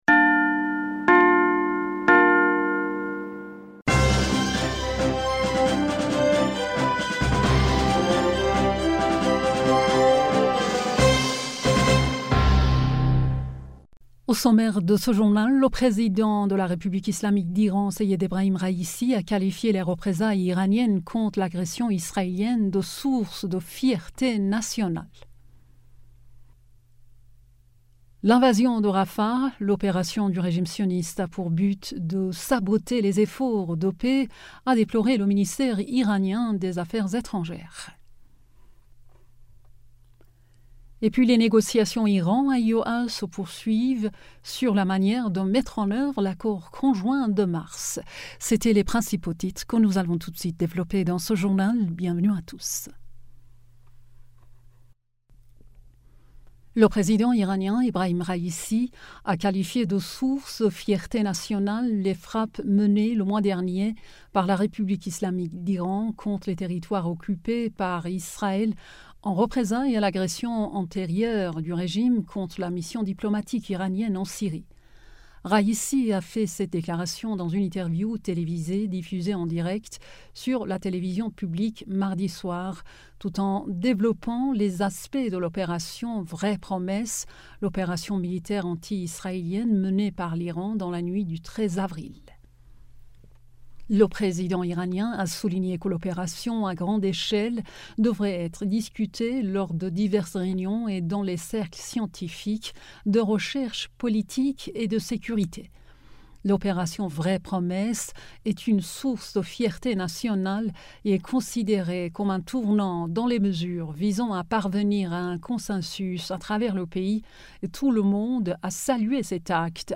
Bulletin d'information du 08 Mai